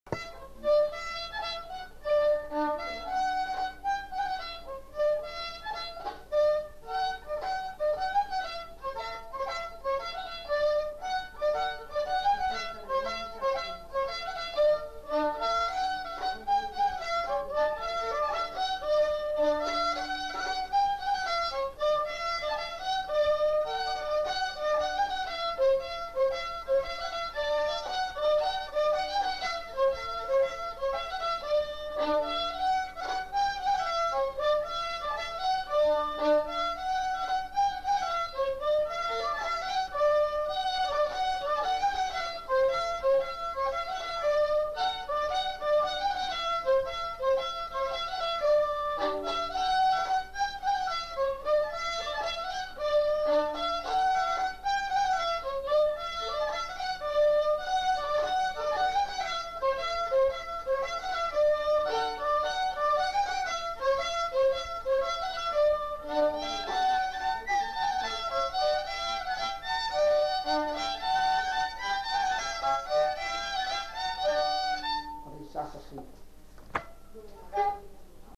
Gigue
Lieu : Saint-Michel-de-Castelnau
Genre : morceau instrumental
Instrument de musique : violon
Notes consultables : 2 violons.